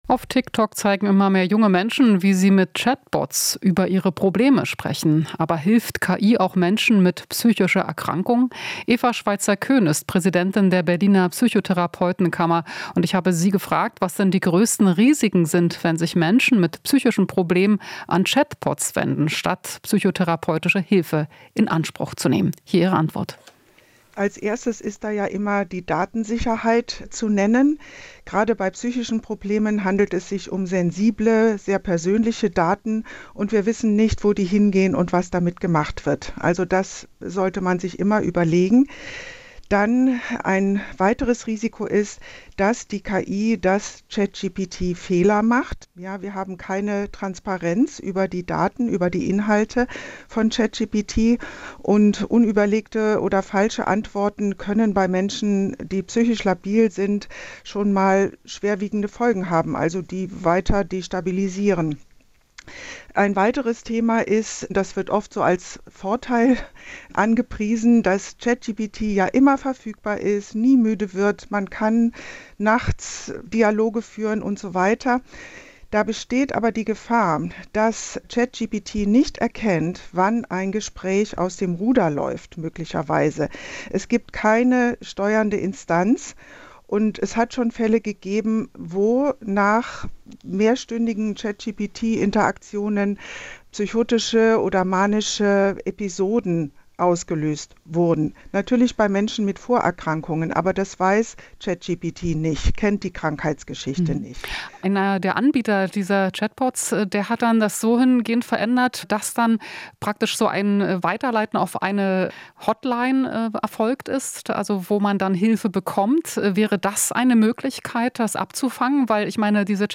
Interview - Therapeutin warnt vor ChatGPT als psychischem Berater